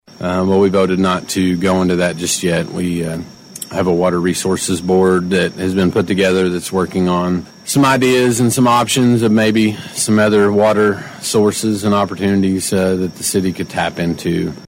Appearing on City Matters on KWON, Bartlesville City Councilor Trevor Dorsey explains